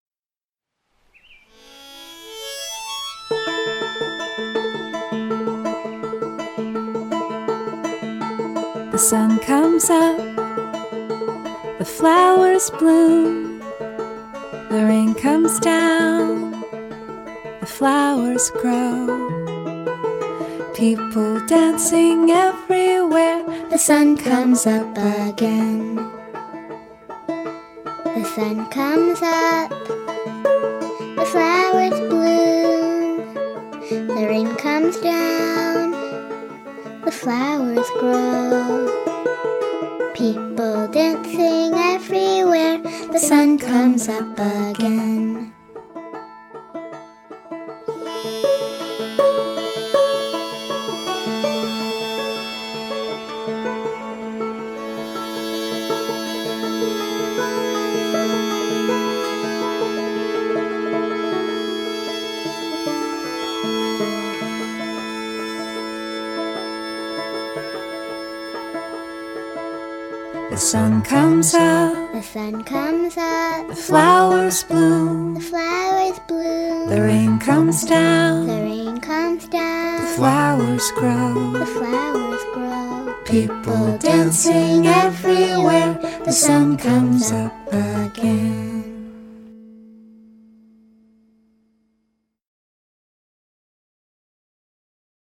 無垢でポップな魅力に溢れた傑作です！